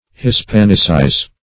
hispanicize - definition of hispanicize - synonyms, pronunciation, spelling from Free Dictionary
Search Result for " hispanicize" : The Collaborative International Dictionary of English v.0.48: Hispanicize \His*pan"i*cize\, v. t. To give a Spanish form or character to; as, to Hispanicize Latin words.
hispanicize.mp3